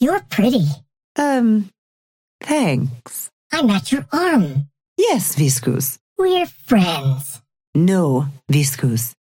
Lady Geist and Viscous conversation 1